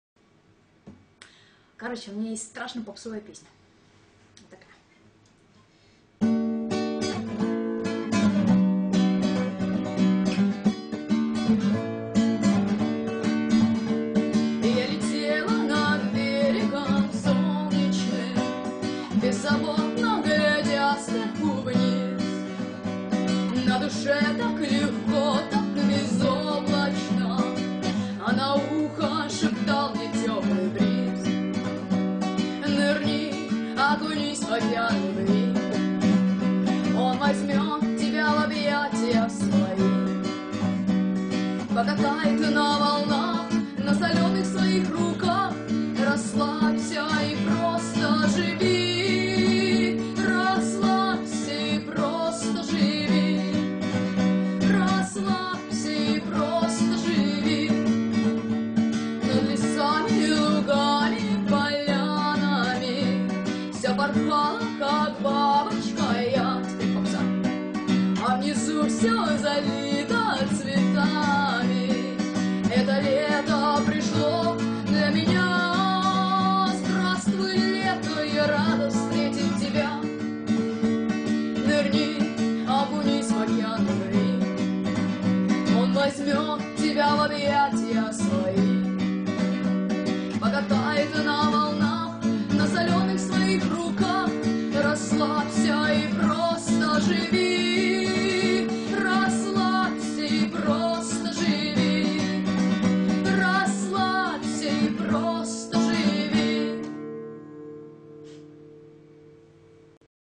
Я написала эту попсу лет 5 назад.